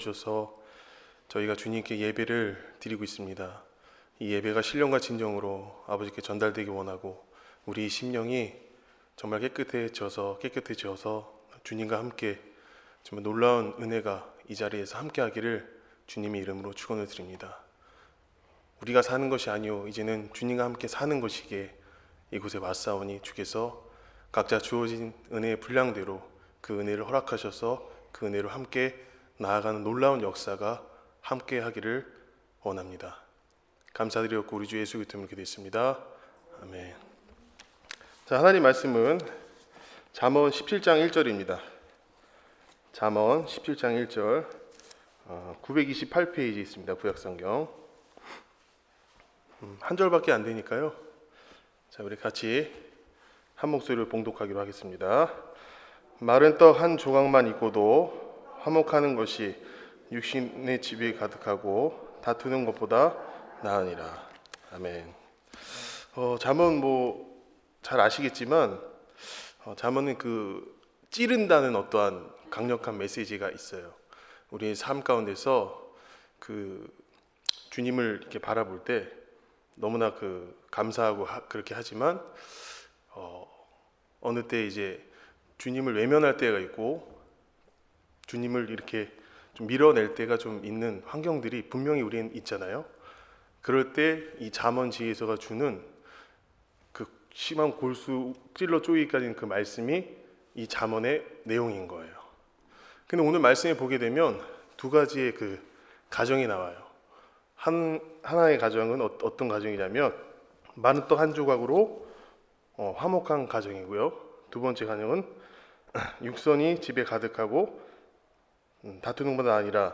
수요예배 잠언 17장 1절